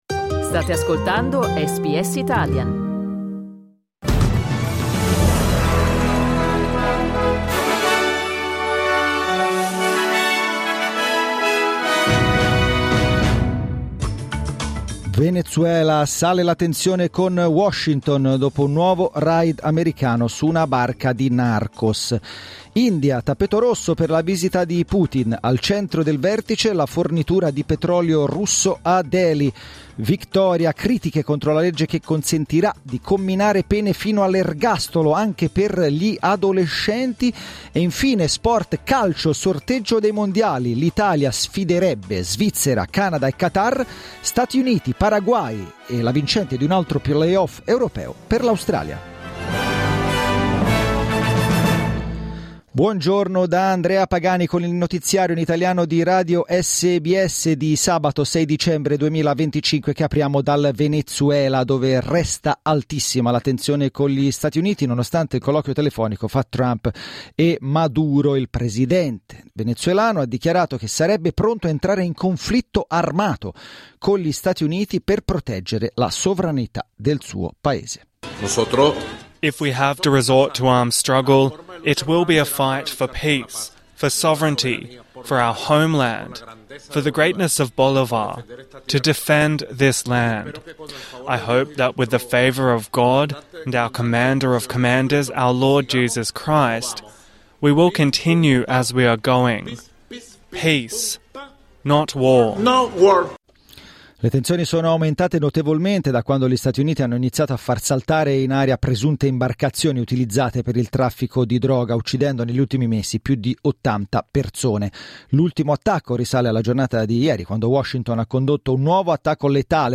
Giornale radio sabato 6 dicembre 2025 | SBS Italian
Il notiziario di SBS in italiano.